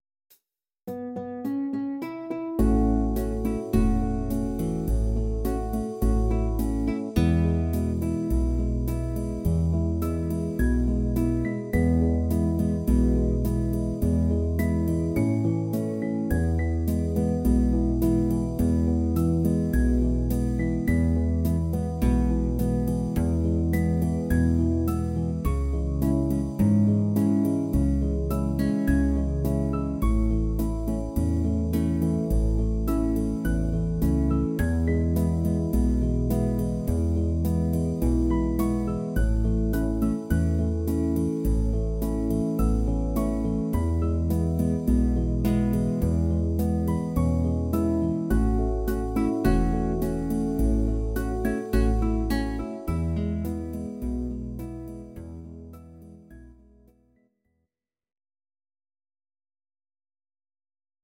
Audio Recordings based on Midi-files
Oldies, Country, 1950s